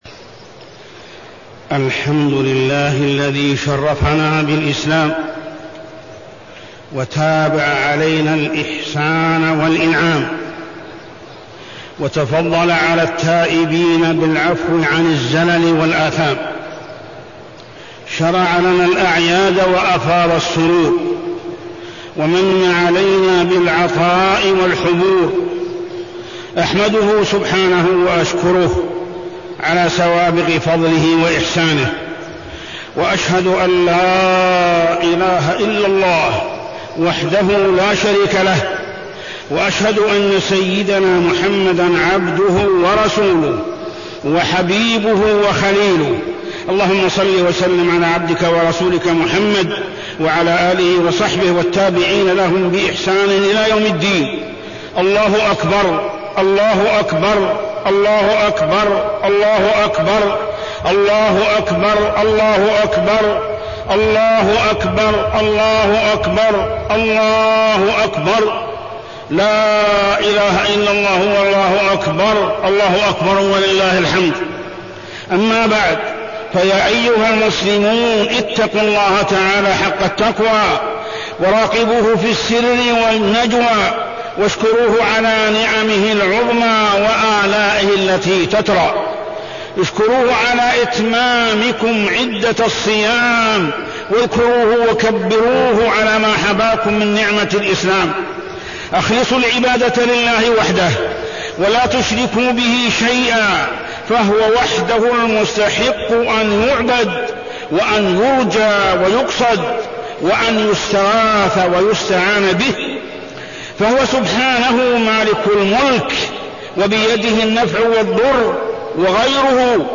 خطبة عيد الفطر -واقع الأمة الإسلامية
تاريخ النشر ١ شوال ١٤٢٣ هـ المكان: المسجد الحرام الشيخ: محمد بن عبد الله السبيل محمد بن عبد الله السبيل خطبة عيد الفطر -واقع الأمة الإسلامية